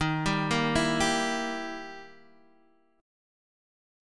Gm6/Eb chord